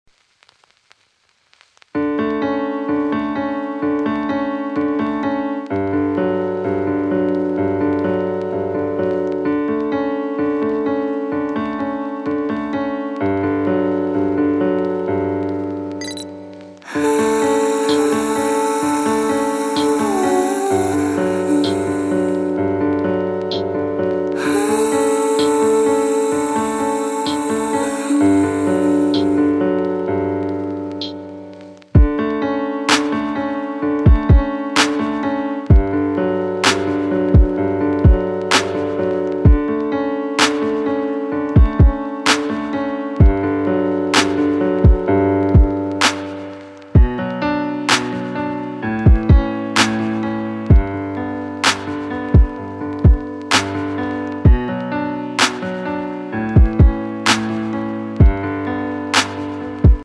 歌曲调式：D大调